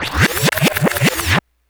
FX.wav